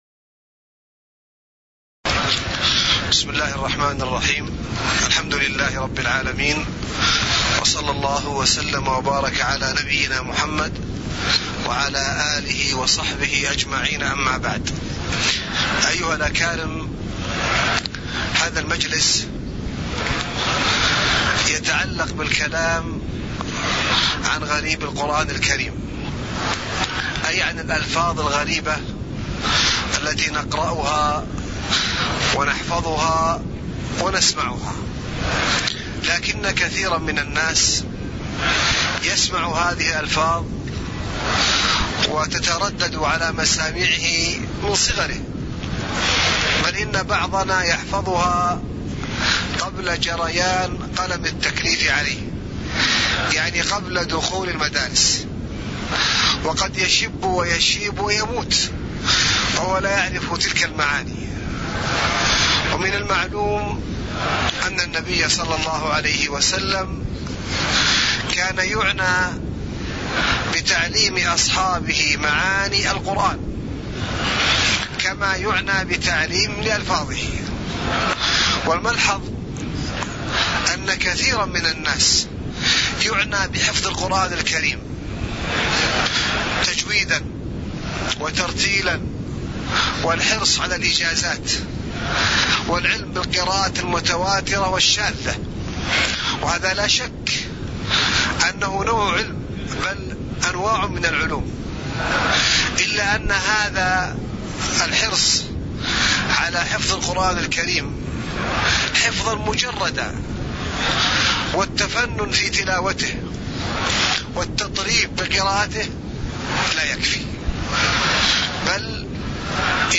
تاريخ النشر ١٢ صفر ١٤٣٨ هـ المكان: المسجد النبوي الشيخ